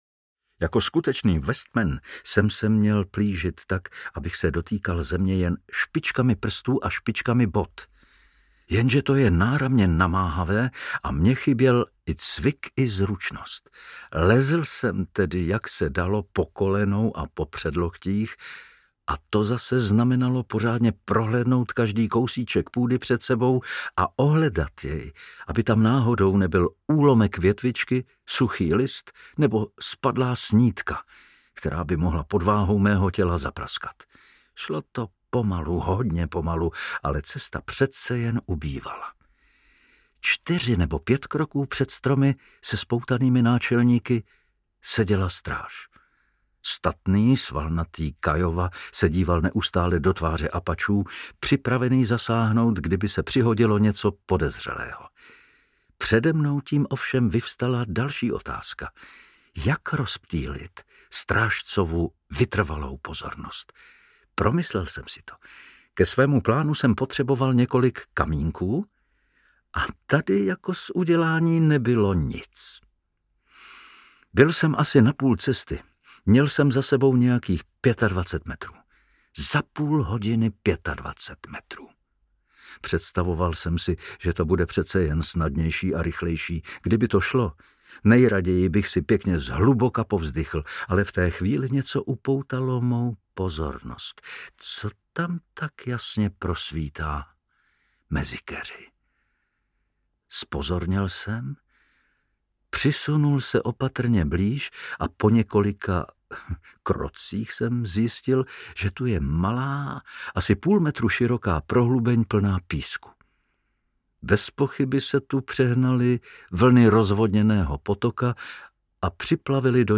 Vinnetou audiokniha
Ukázka z knihy
Čte Pavel Soukup.
Vyrobilo studio Soundguru.